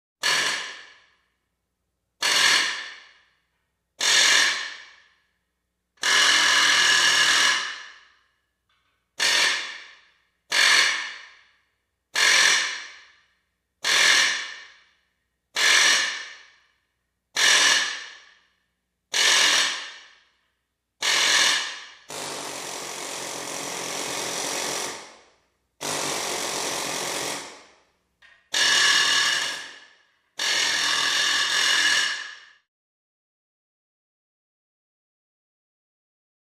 Air Chisel; Harsh Pneumatic Metallic Ratchet Sound With Garage Reverb. Short & Long Bursts.